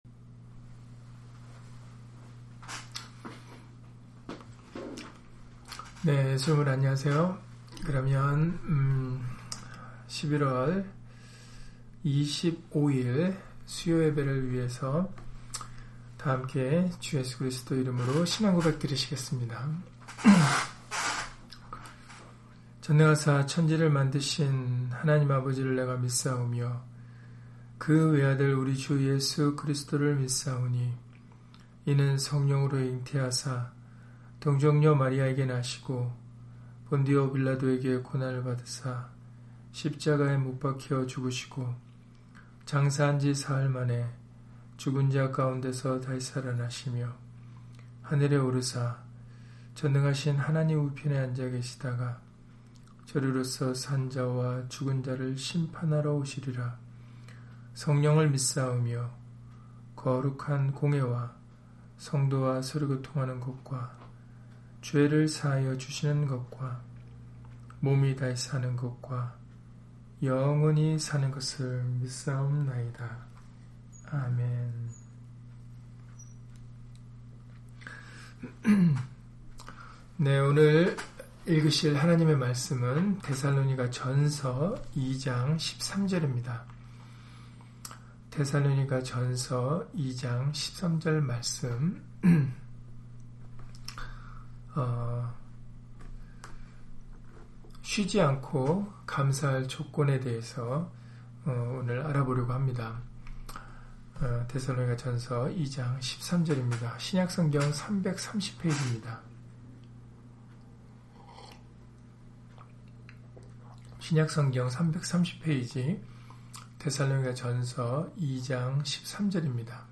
데살로니가전서 2장 13절 [쉬지 않고 감사할 조건] - 주일/수요예배 설교 - 주 예수 그리스도 이름 예배당